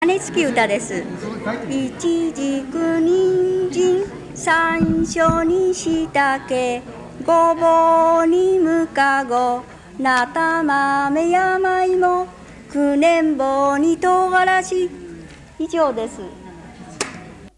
羽根つき歌 遊戯歌